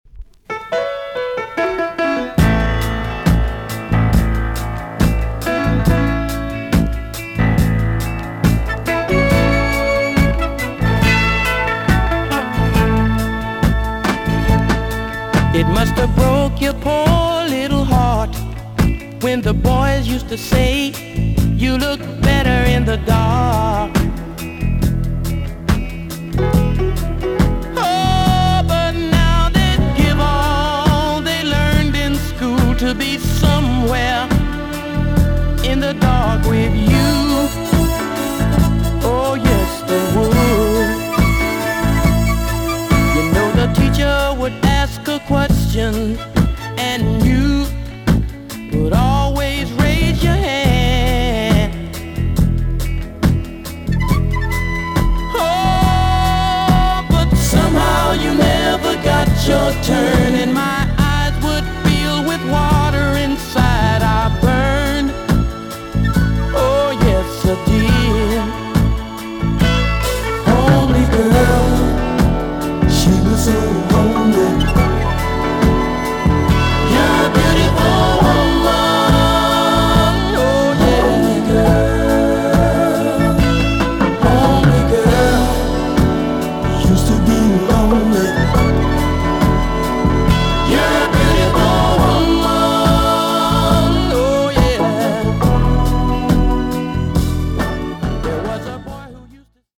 EX 音はキレイです。
1973 , WICKED JAMAICAN SOUL TUNE!!